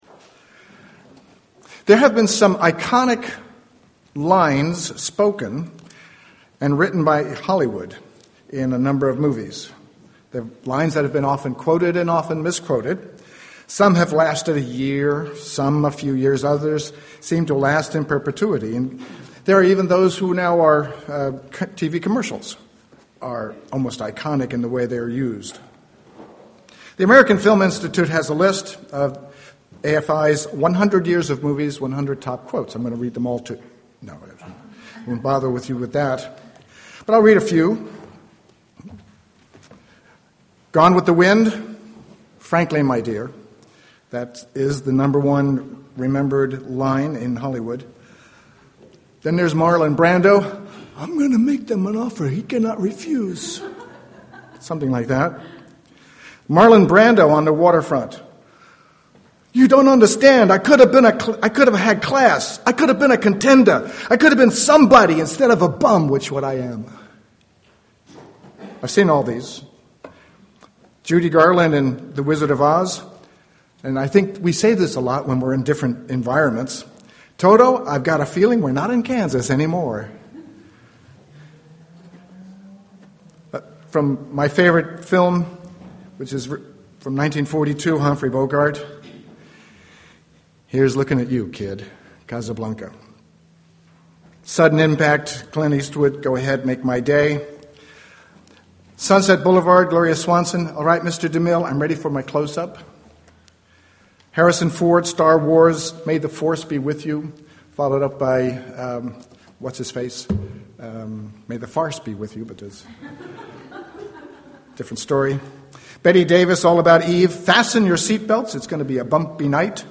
Given in San Jose, CA